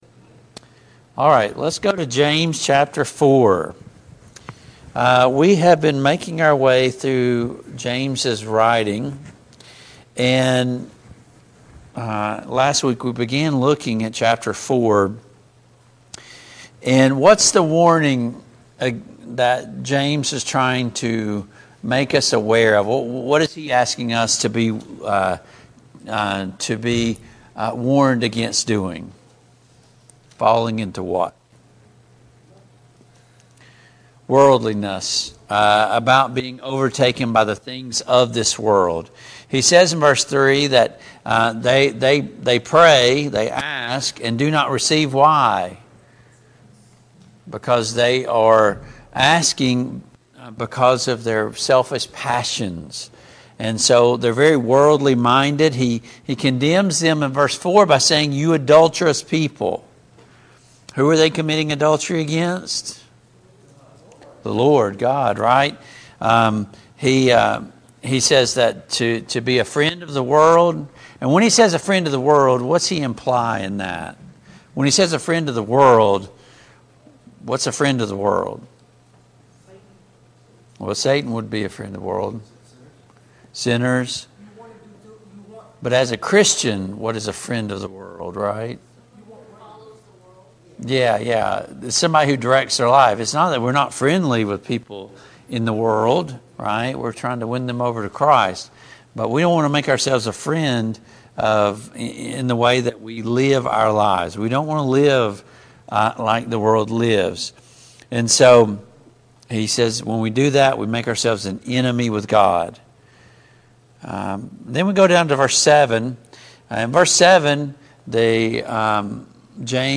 James 4:11-13 Service Type: Family Bible Hour Topics: Judgements , slander « Why God does and does not answer our prayers?